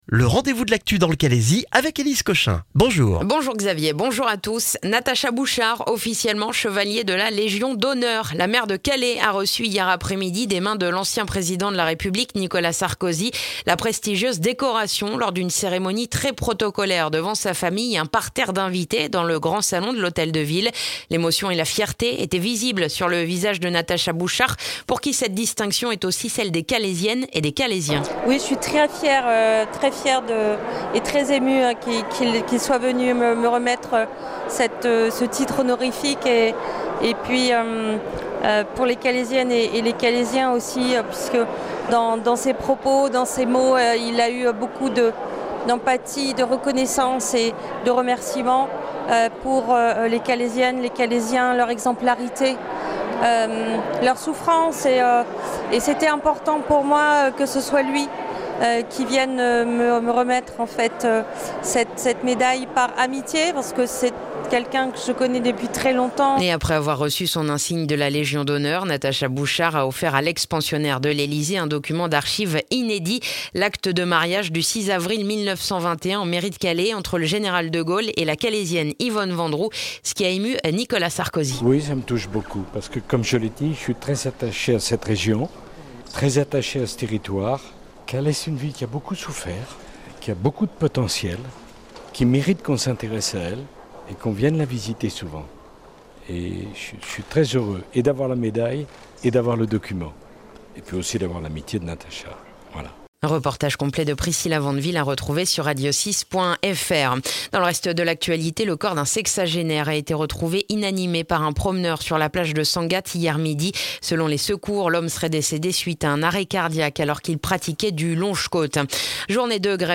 Le journal du jeudi 23 septembre dans le calaisis